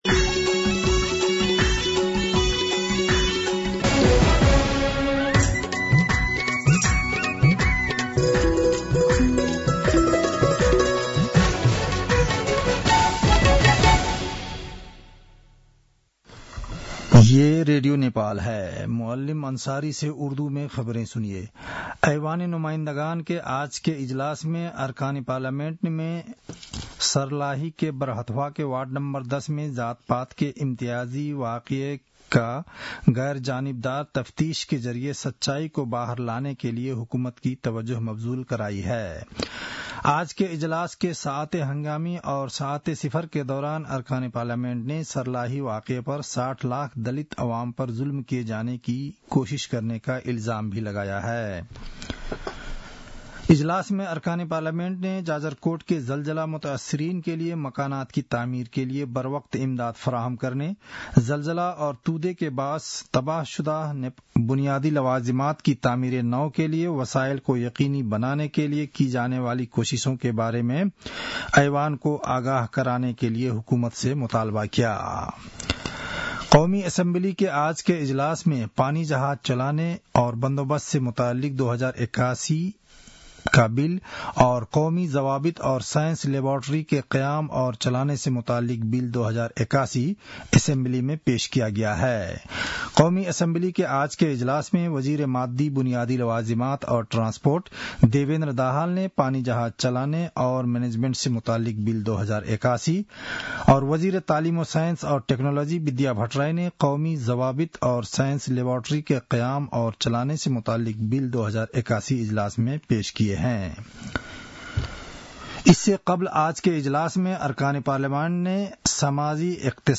उर्दु भाषामा समाचार : २५ माघ , २०८१
Urdu-news-10-24.mp3